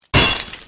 TF2 Wrench.ogg